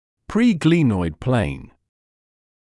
[ˌpriː’gliːnɔɪd pleɪn][ˌприː’глиːнойд плэйн]предсуставная плоскость